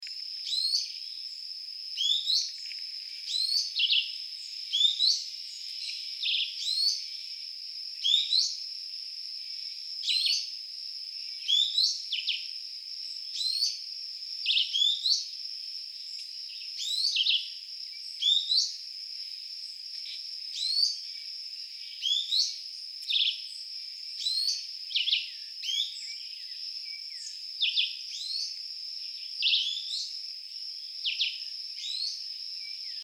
Ochre-cheeked Spinetail (Synallaxis scutata)
Life Stage: Adult
Location or protected area: Parque Nacional Calilegua
Condition: Wild
Certainty: Observed, Recorded vocal